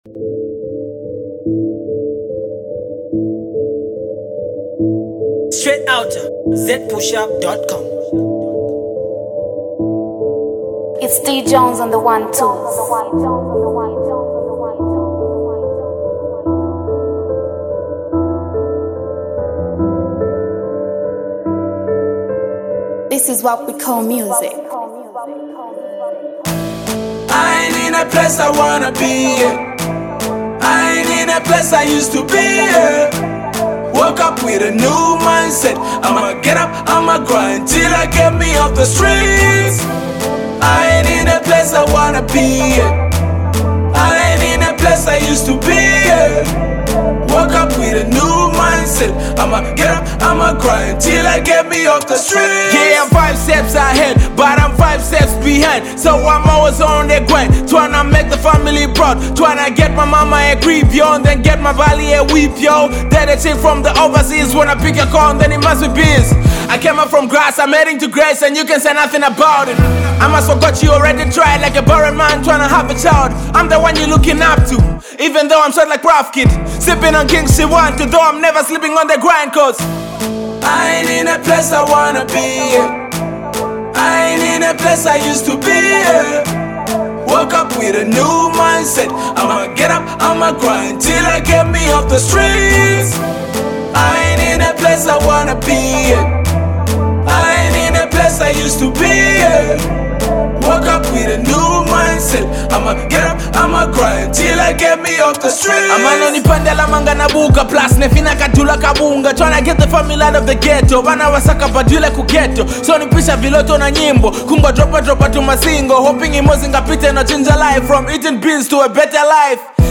dope hustlers track